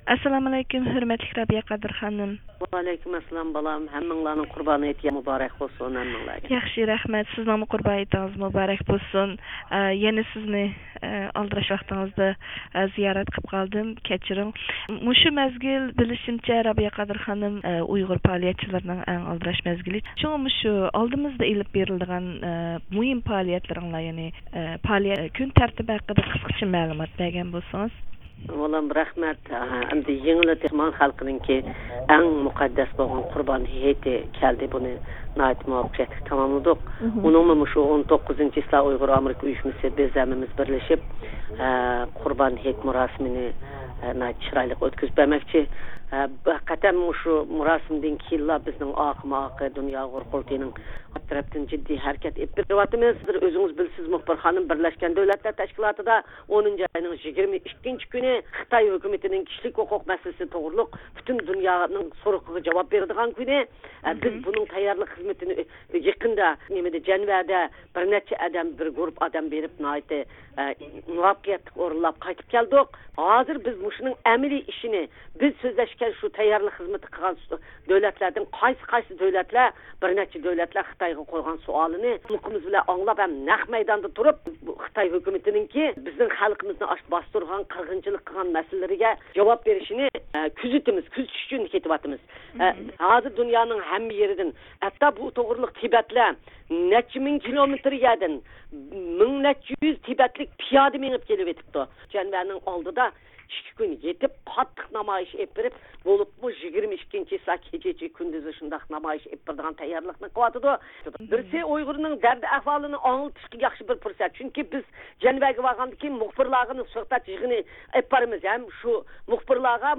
كېلەر ھەپتە ب د ت دا چاقىرىلىدىغان خىتاينىڭ ئىنسانىي ھوقۇق خاتىرىسىگە قاراپ چىقىش يىغىنى ۋە 12-نويابىر «شەرقىي تۈركىستان جۇمھۇرىيەت كۈنى» يېتىپ كېلىش ئالدىدىكى جىددىي ۋەزىيەتتە زىيارىتىمىزنى قوبۇل قىلغان ئۇيغۇر مىللىي ھەرىكىتى رەھبىرى رابىيە قادىر خانىم مەزكۇر پائالىيەتلەرنىڭ ئەھمىيىتى ھەققىدە توختالدى ۋە دۇنيانىڭ ھەر قايسى جايلىرىدىكى ئۇيغۇرلارنى پائالىيەتلەرگە قاتنىشىپ، ئۆزىنىڭ مىللىي بۇرچىنى ئادا قىلىشقا چاقىردى.